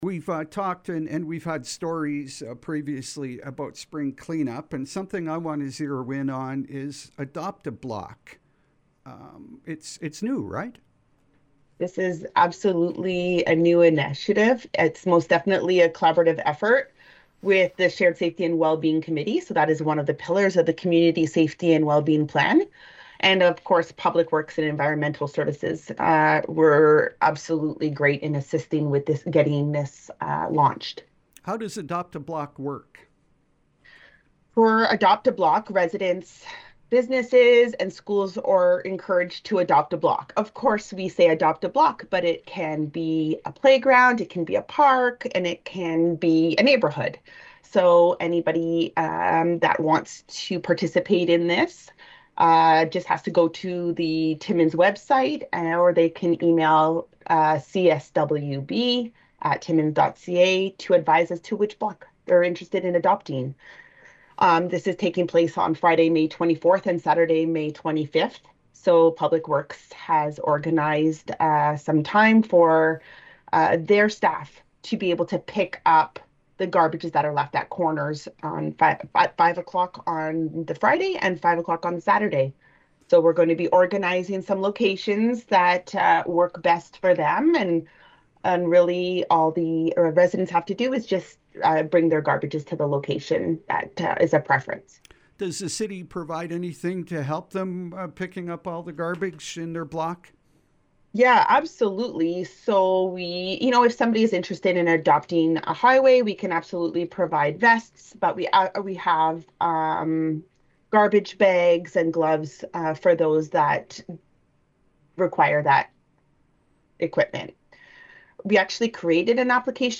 Here is audio of our interview: